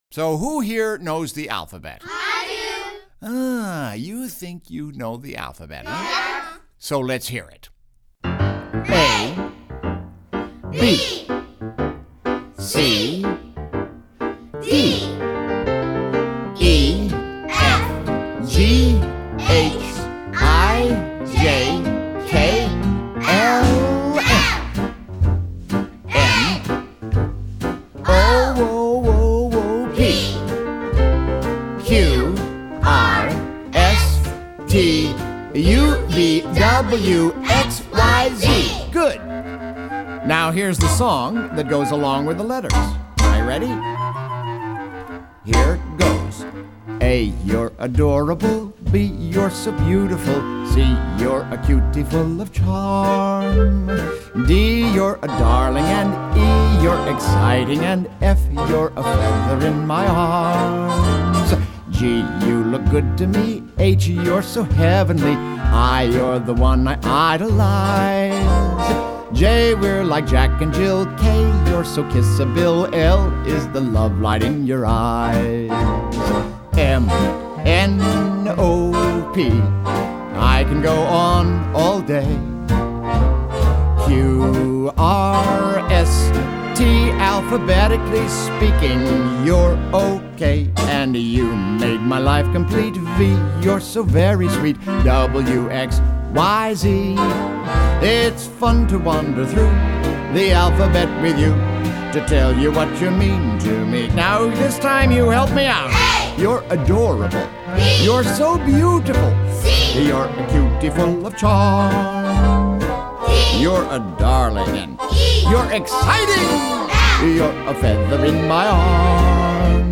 with a childrens’ chorus